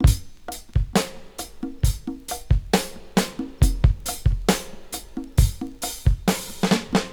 • 67 Bpm Drum Beat G# Key.wav
Free drum loop sample - kick tuned to the G# note. Loudest frequency: 1810Hz
67-bpm-drum-beat-g-sharp-key-RqF.wav